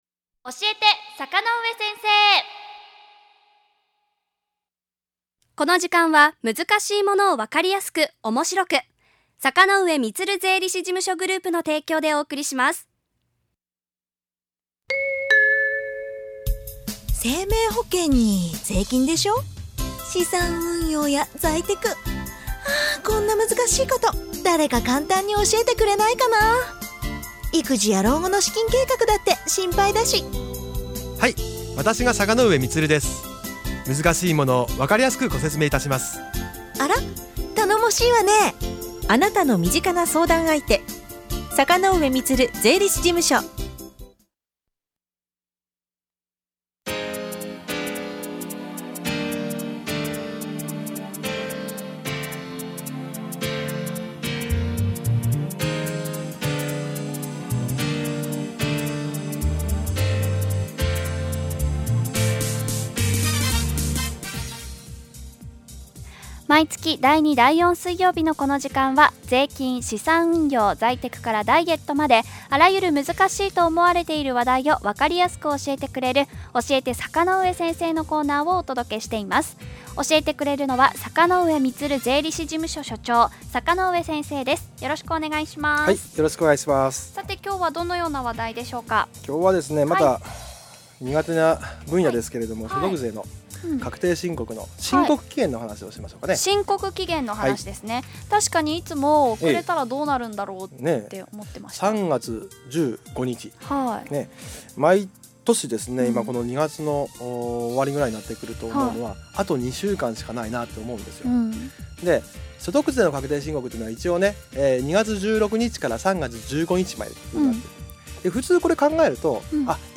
この音声は、2月27日のラジオたかおか放送内容です。